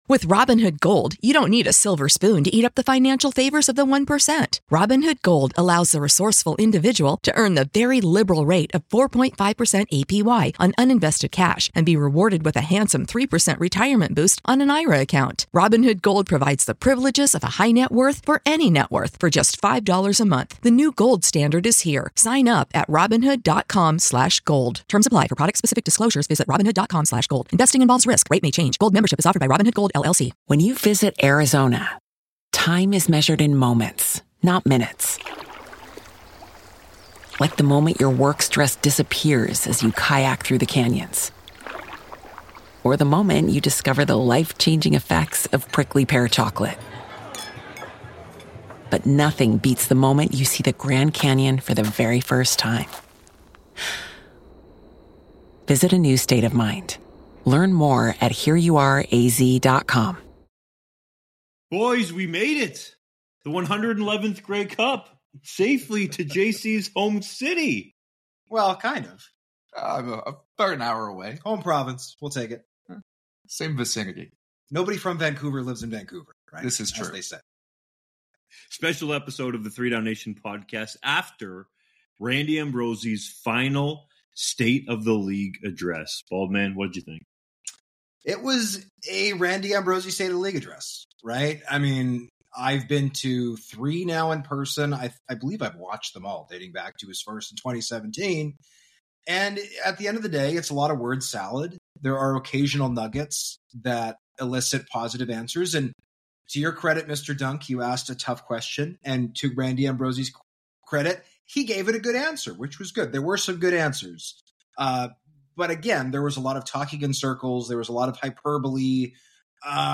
speak from a poorly-lit hotel couch